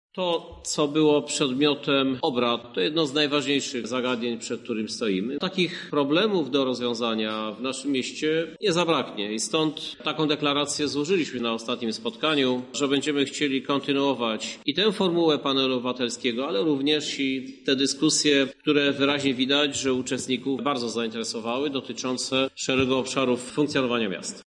O tym, jak ma rozwijać się ta inicjatywa mówi prezydent Lublina, Krzysztof Żuk: